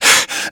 mrk_breathing_hurt12.wav